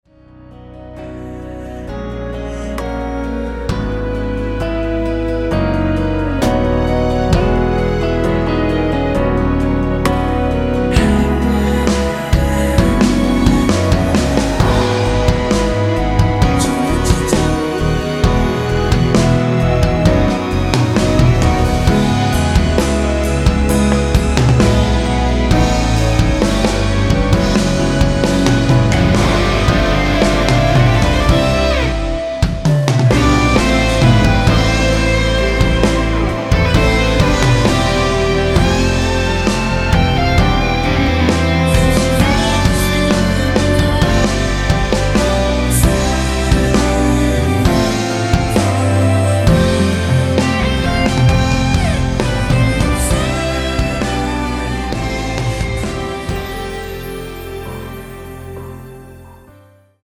원키에서(-2)내린 (1절앞+후렴)으로 진행되는 멜로디와 코러스 포함된 MR입니다.(미리듣기 확인)
앞부분30초, 뒷부분30초씩 편집해서 올려 드리고 있습니다.
중간에 음이 끈어지고 다시 나오는 이유는